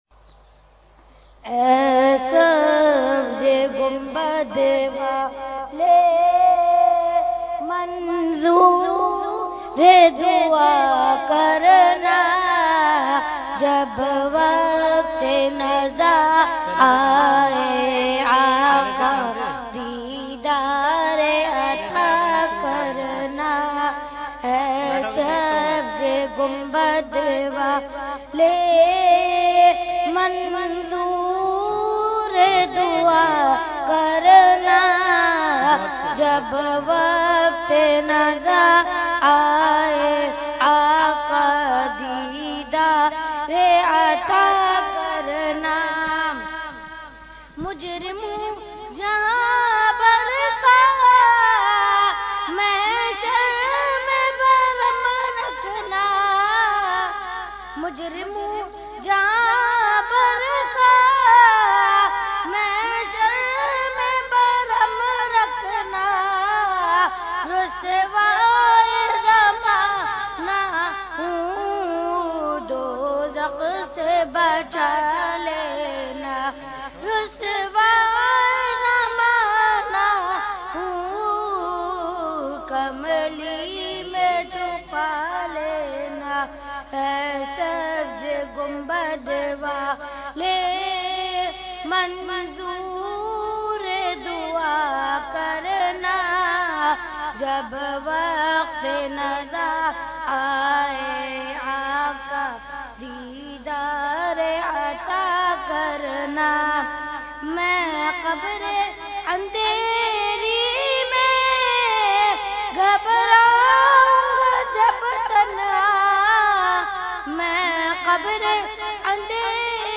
Category : Naat | Language : UrduEvent : Urs Qutbe Rabbani 2017